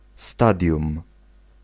Ääntäminen
IPA : /ˈfeɪz/ US : IPA : [ˈfeɪz]